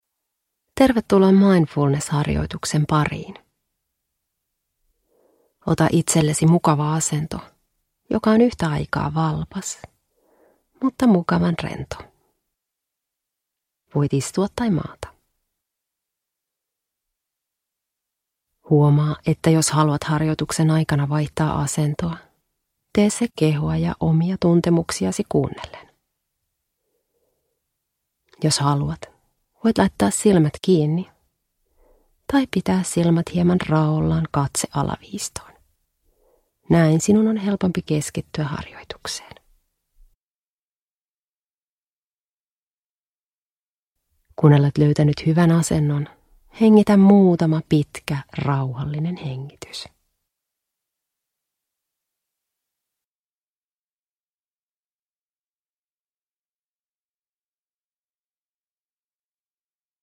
Mindfulness-harjoitus 10 min – Ljudbok – Laddas ner